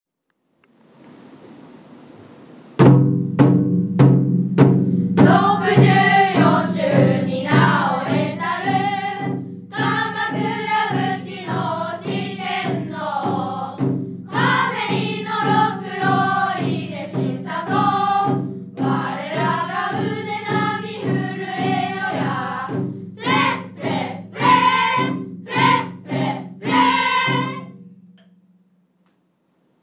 9月29日（金）業前、5･6年生の子供たちが音楽室に集まり、「亀井小応援歌」の練習をしていました。
亀井小を卒業した方々の記憶から復活させたものです。全校で練習するために、まずは5･6年生が覚え、他の学年の練習用に録音しました。150周年を祝う会まで、まだ時間がありますが、すでに気合いの込められた歌声に感動しました。
応援歌.mp3